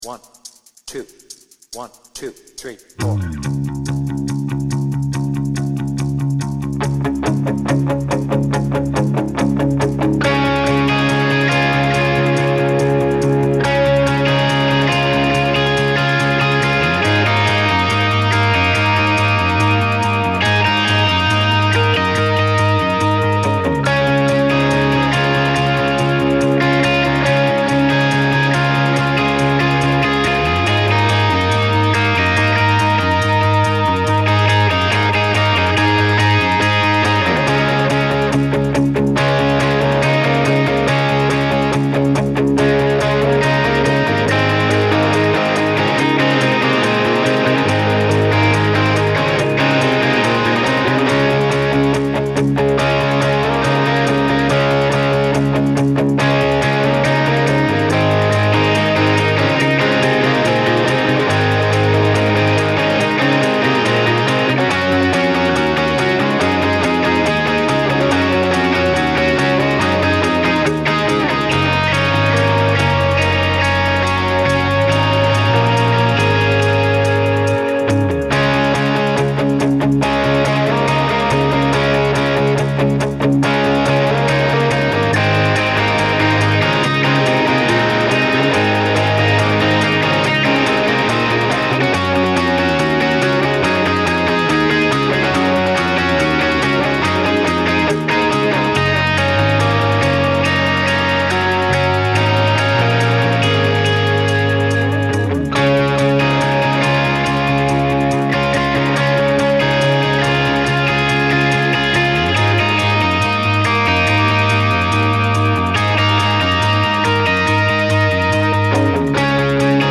Without vocals
Based on the Slane Castle version